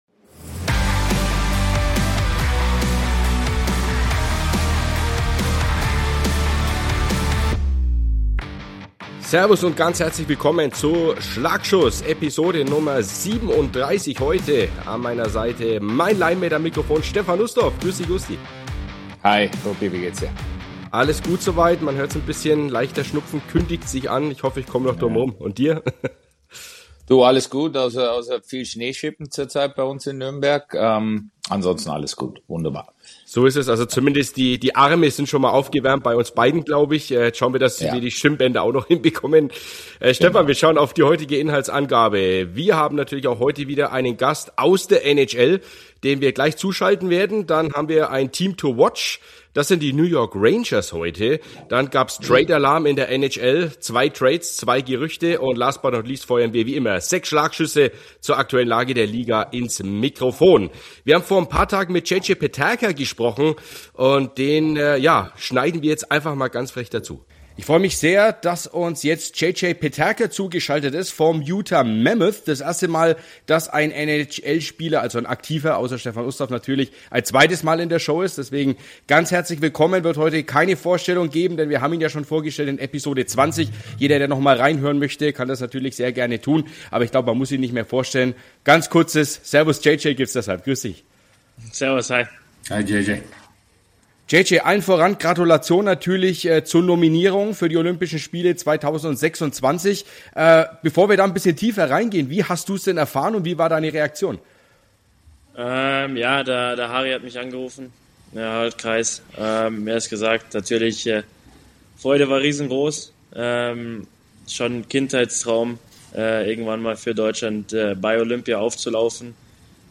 Mit JJ Peterka kommt erstmals ein NHL-Profi zurück in den Podcast. Der Stürmer des Utah Mammoth spricht ausführlich über die anstehenden Olympischen Spiele Milano Cortina 2026.